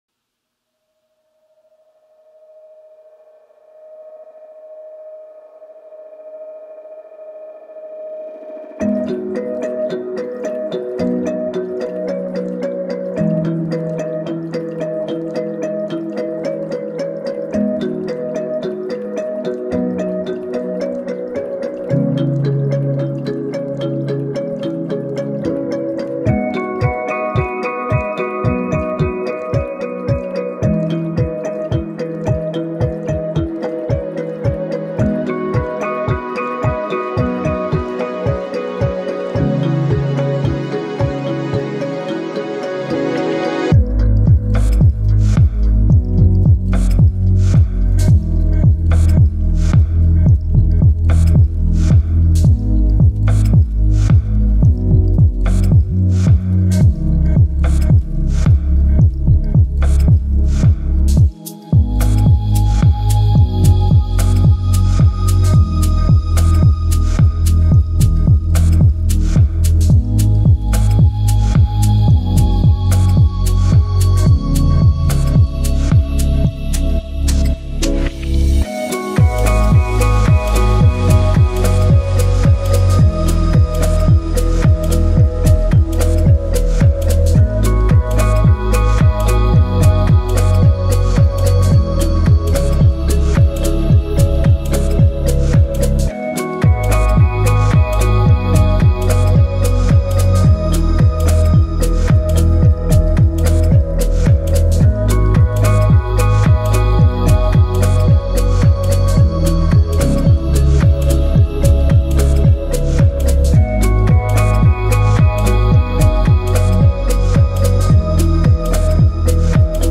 Tags2010s 2014 ambient Electronic Europe-Asia